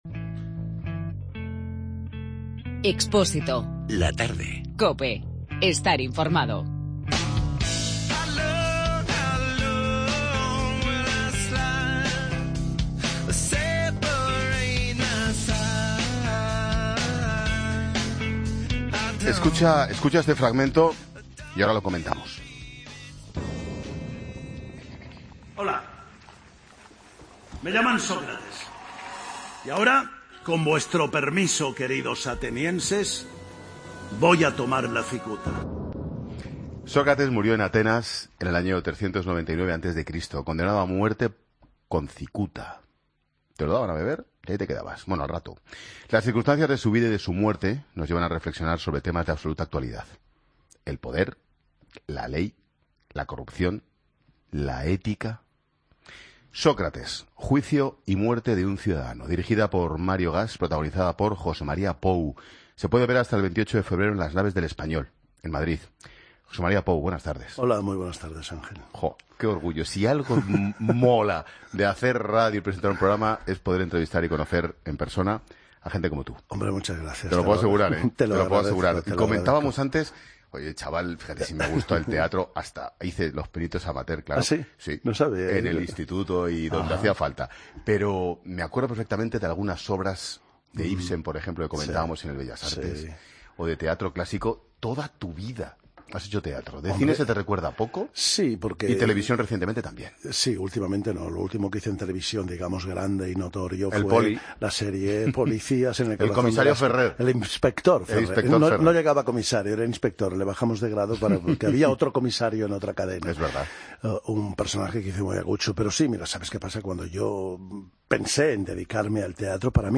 Escucha al actor José María Pou en La Tarde